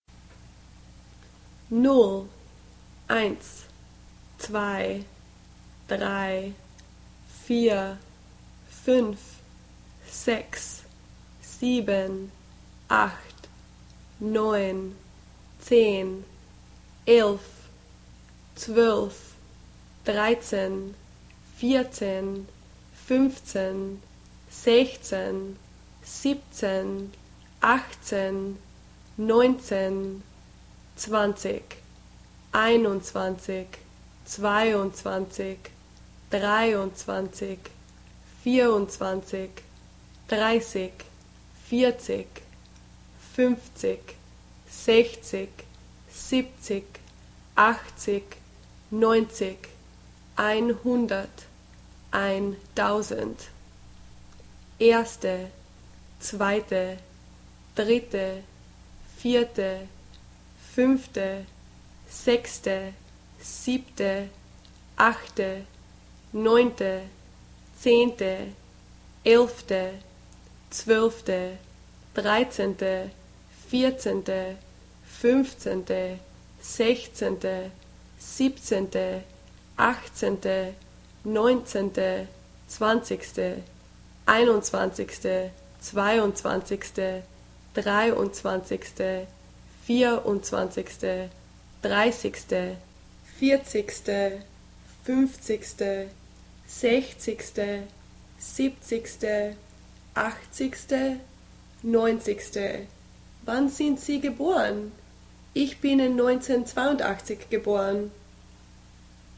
0 null nool 1 eins ines
Sometimes zwo (tsvoh) is used instead of zwei to avoid confusion with drei when talking on the telephone.